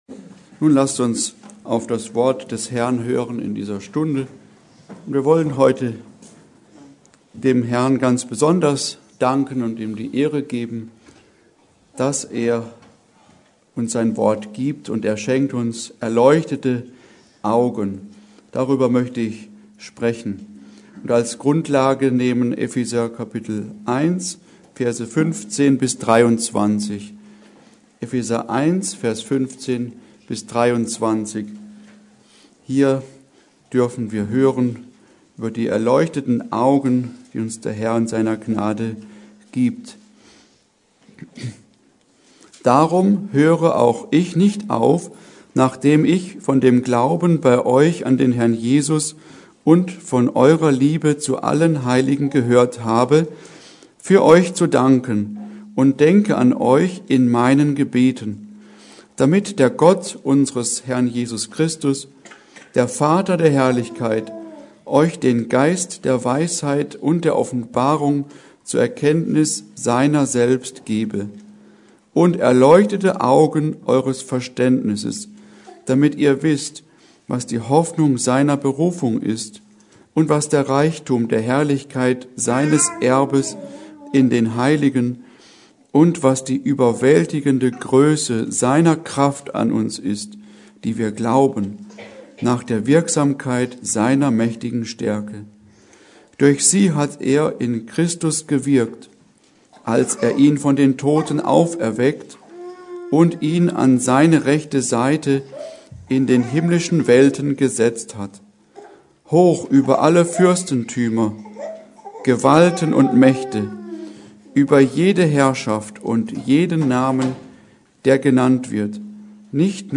Predigt: Erleuchtete Augen